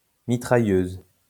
A mitrailleuse (French pronunciation: [mitʁajøz]